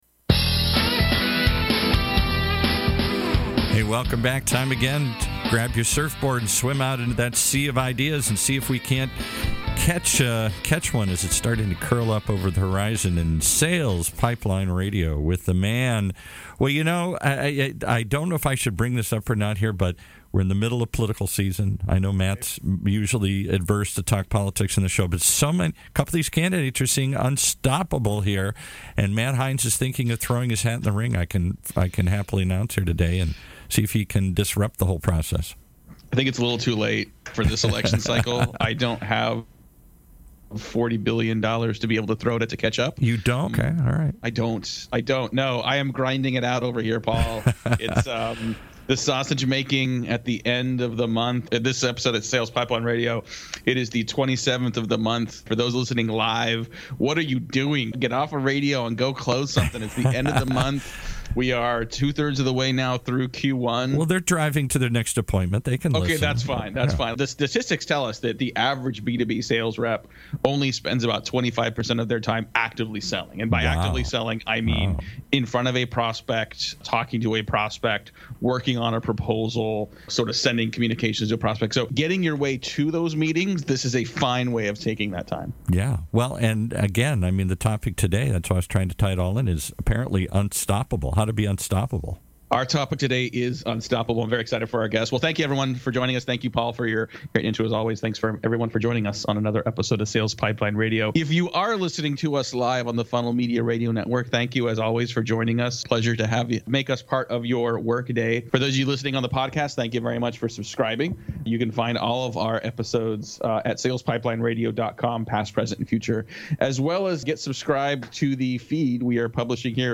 I interview the best and brightest minds in sales and Marketing.